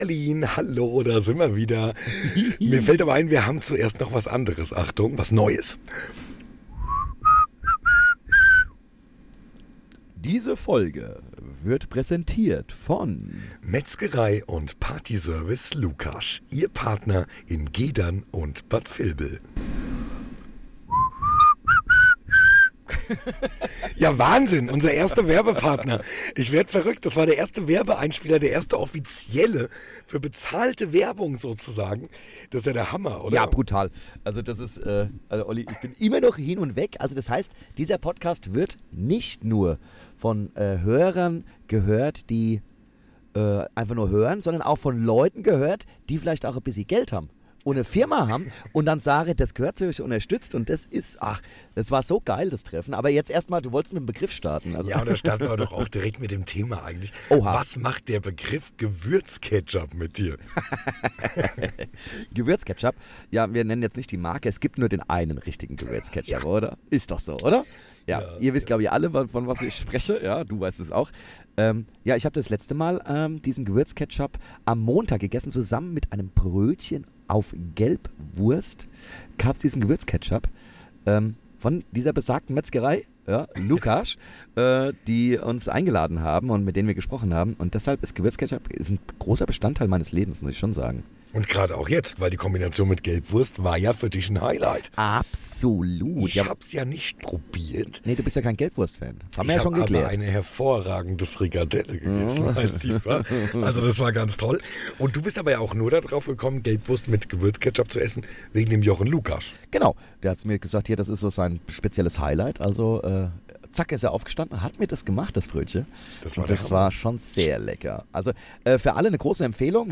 Von Metzgerei-Werbung über Gewürzketchup-Erfahrungen bis hin zu verstopften Situationen - die beiden Moderatoren lassen keine Pointe aus.